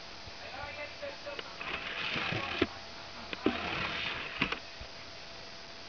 This CD-ROM drive inject & eject sound was recoreded from my 24x CD-ROM drive in my Beige G3 computer.
cdrom.aiff